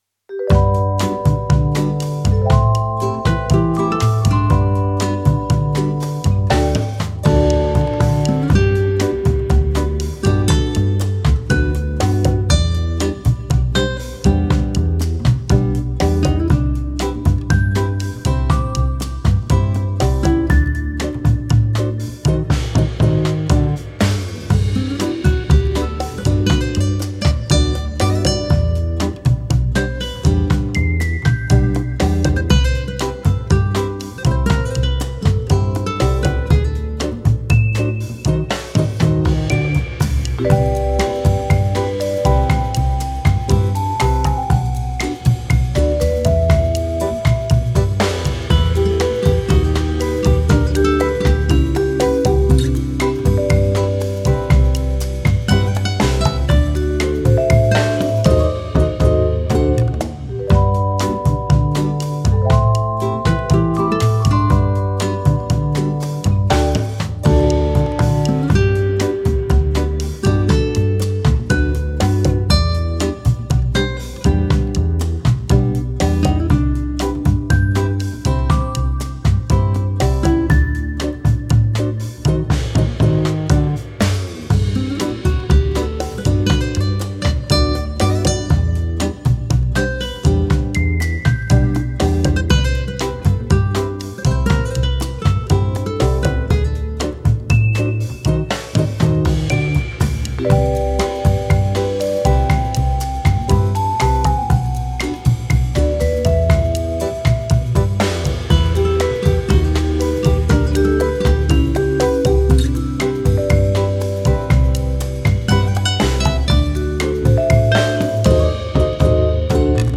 Mit etwas Funmusic schwimmt sie noch etwas fluppiger, press play!